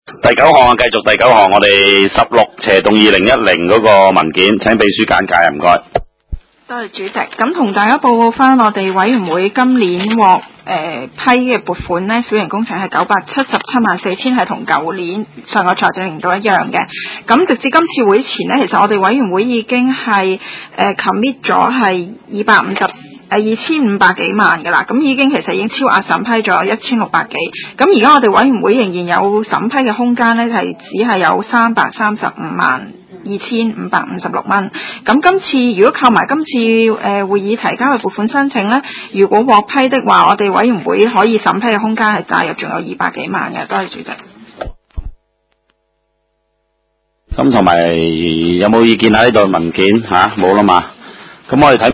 地區工程及設施管理委員會第十五次會議
灣仔民政事務處區議會會議室